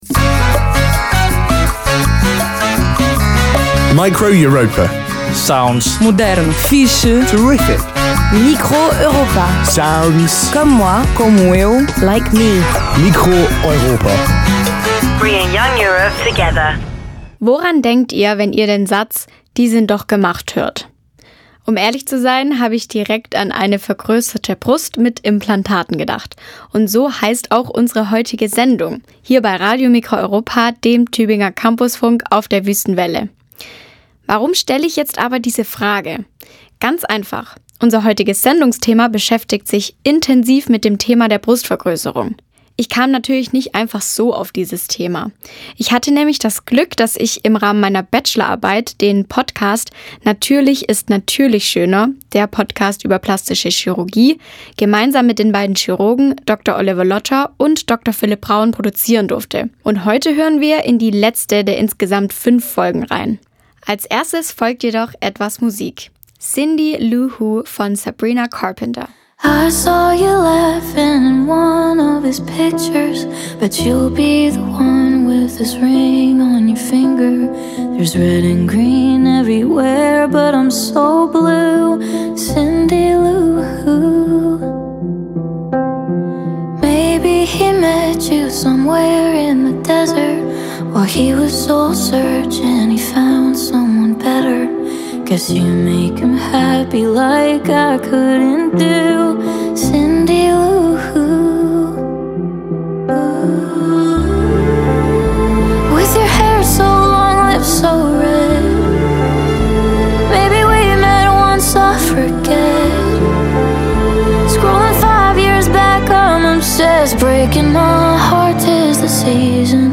Form: Live-Aufzeichnung, geschnitten